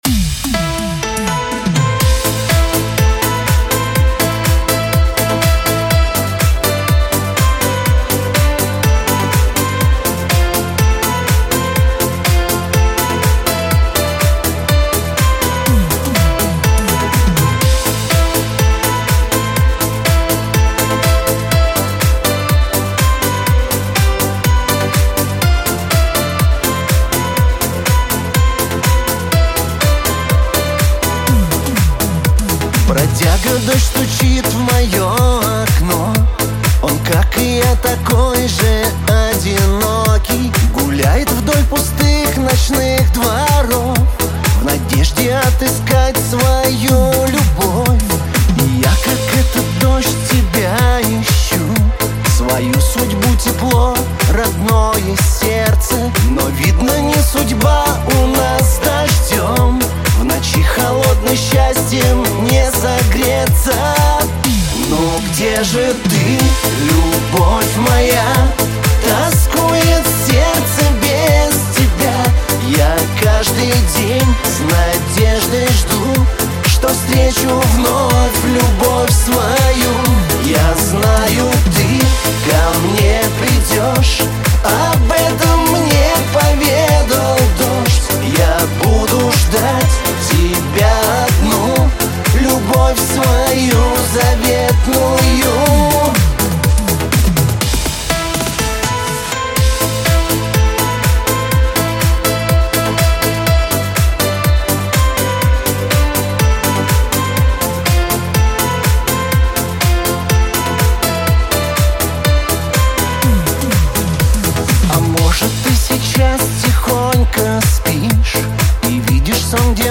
pop
диско
эстрада